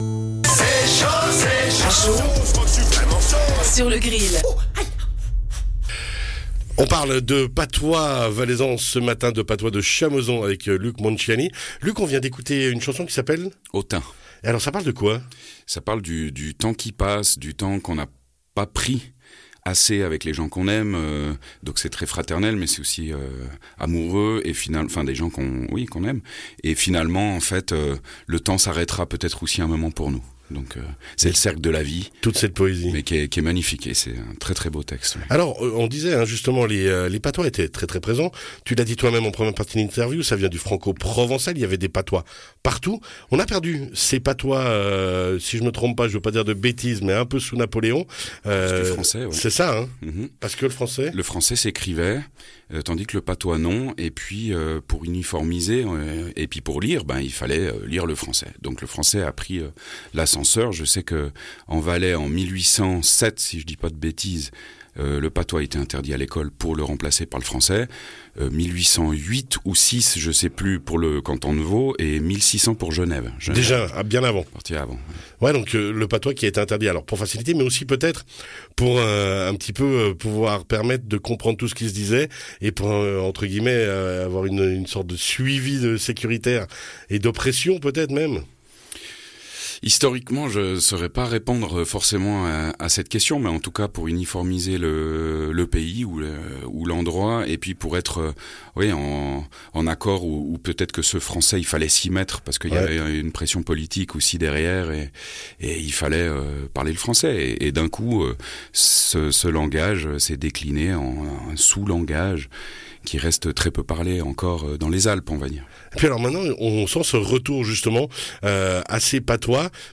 chante le patois valaisan